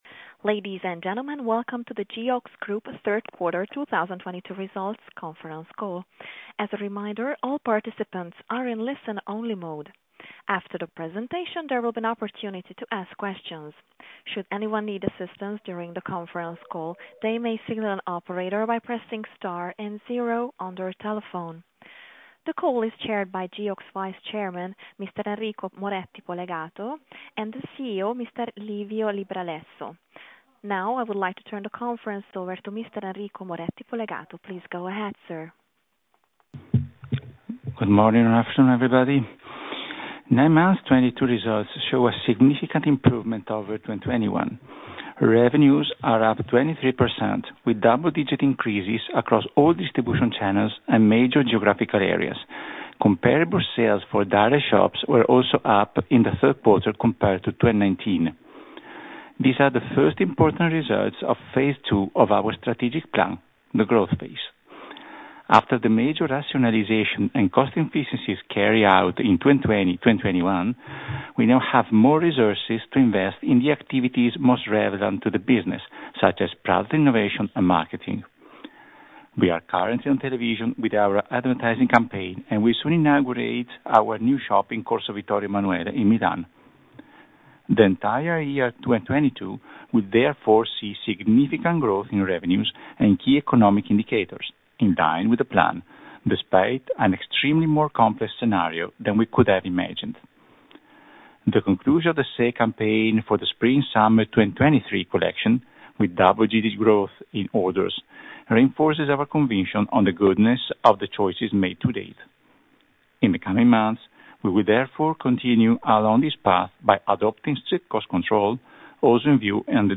Conference Call 9M 2022 Results Presentation